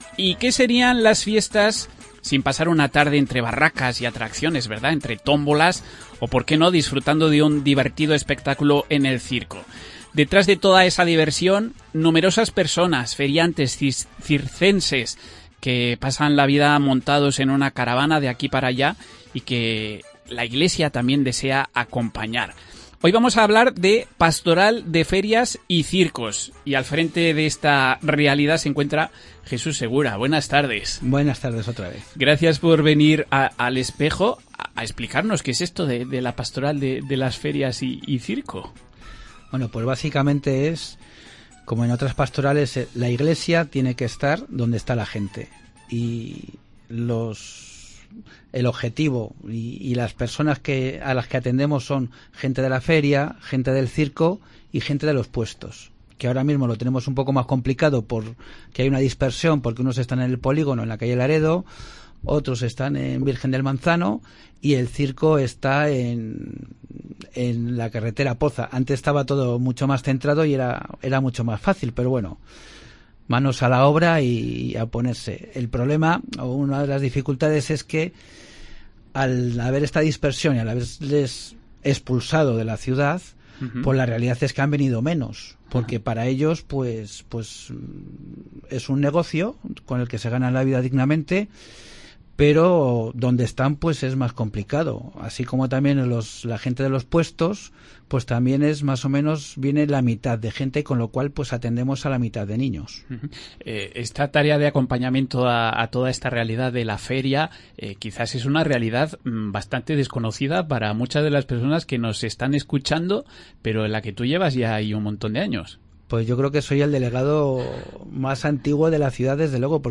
podcast_-pastoral-ferias-y-circoss.mp3